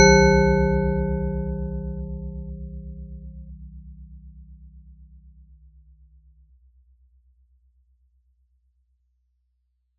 La cloche de Jean-Claude Risset est un son de cloche généré par ordinateur et qui comporte 11 partiels.
bell : fréquence 110Hz ; durée 10 secondes
bell110-10.wav